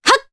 Scarlet-Vox_Jump_Jp.wav